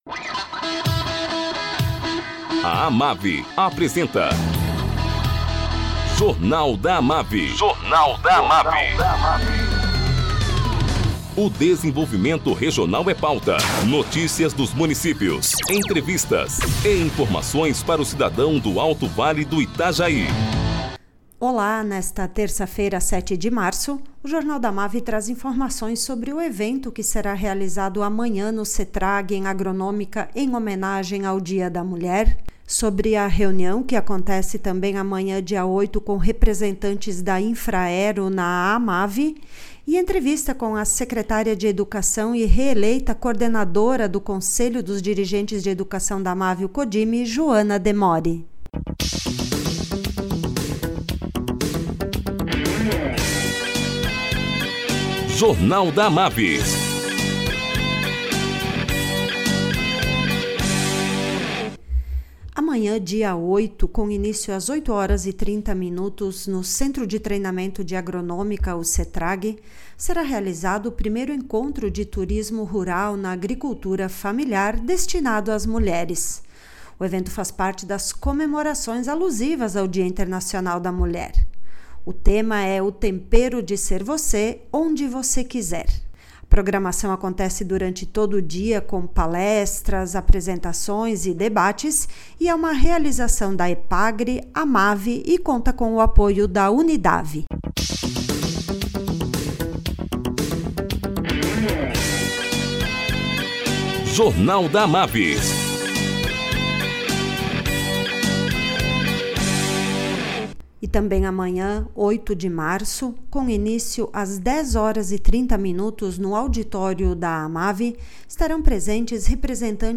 Secretária de Educação de Salete, Joana Demori, é reeleita coordenadora do CODIME e fala sobre o planejmaneto para 2023.